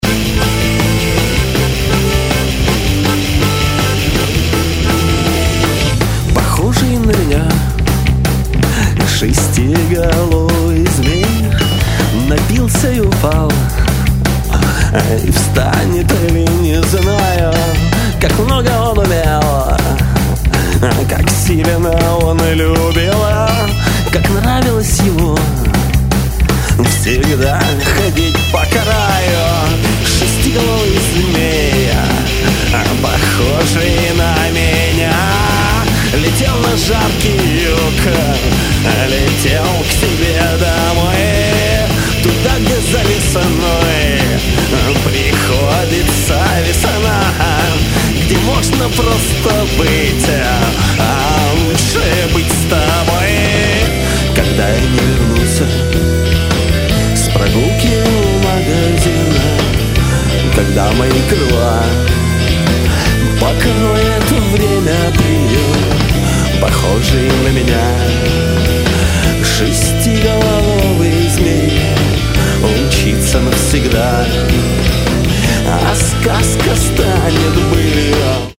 нежный дуэт баяна и флейты
зафуззованными гитарой и голосом
Если добавить к этому нервные басовые партии
и своеобразную переменчивую ритмику барабанов